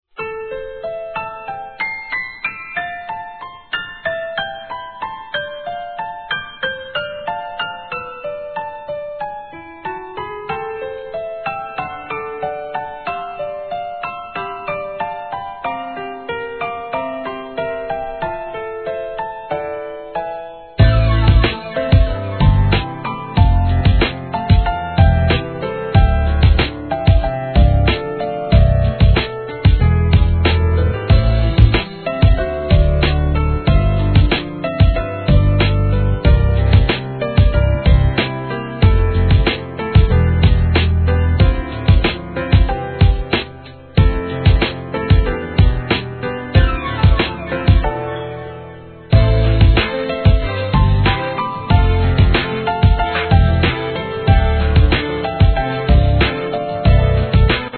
JAPANESE HIP HOP/R&B
ブレイクビーツへ!!